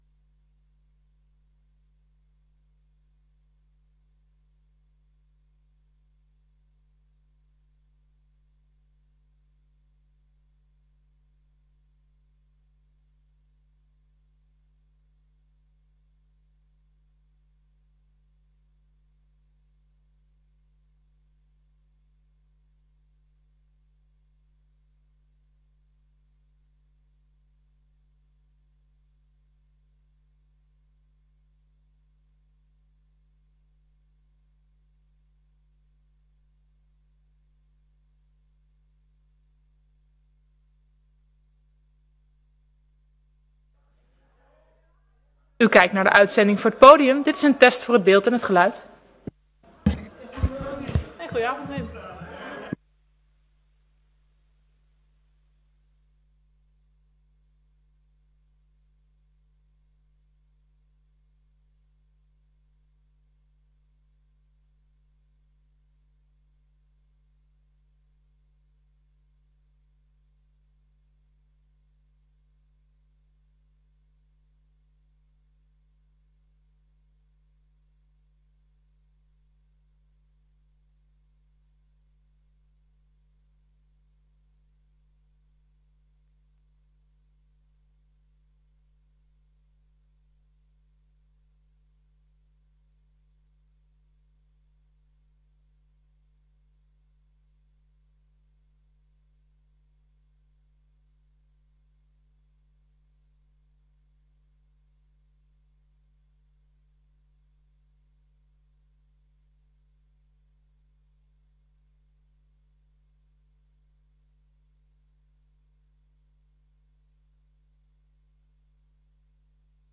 Download de volledige audio van deze vergadering